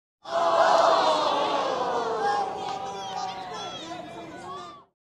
На этой странице собраны звуки, которые ассоциируются с чувством позора: смущенное бормотание, нервный смешок, вздохи разочарования.
Оу, как разочаровано